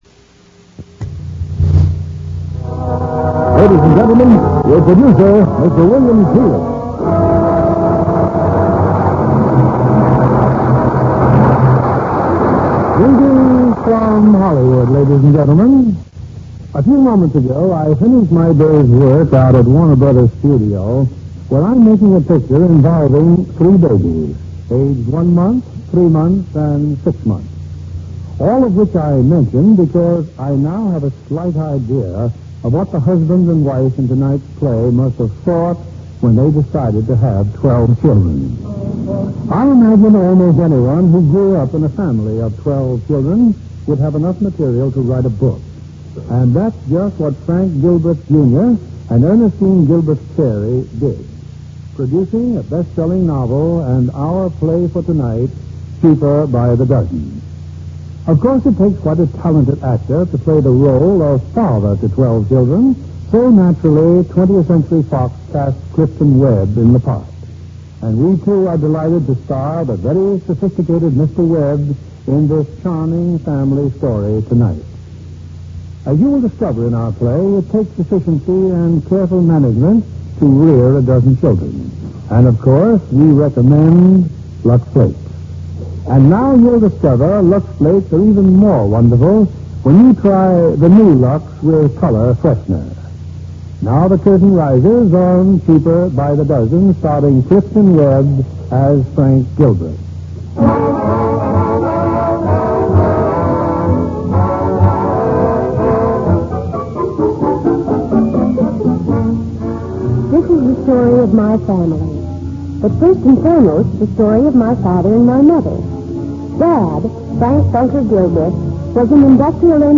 Lux Radio Theater Radio Show
starring Clifton Webb, Rhonda Williams